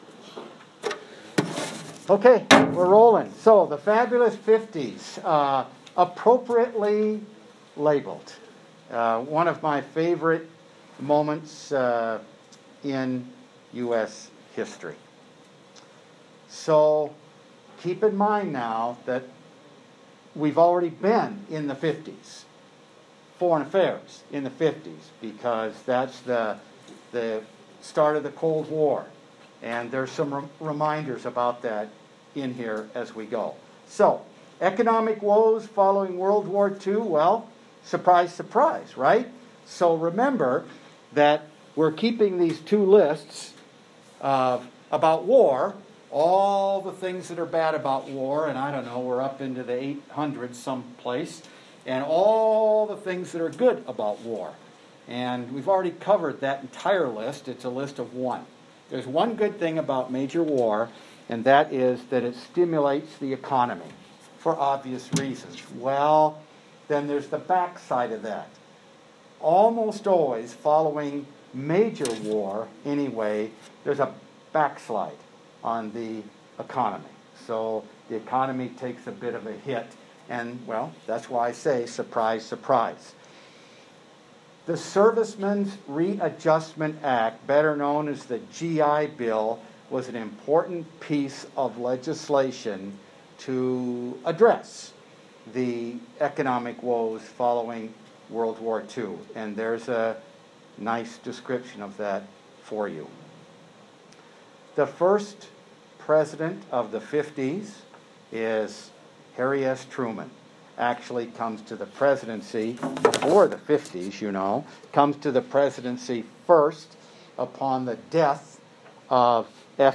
AUDIO LECTURES